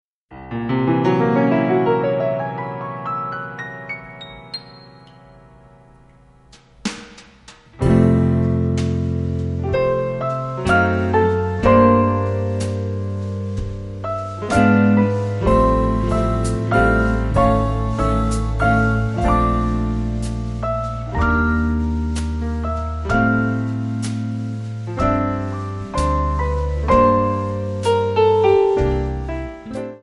Backing track Karaoke
Pop, Oldies, Jazz/Big Band, 1950s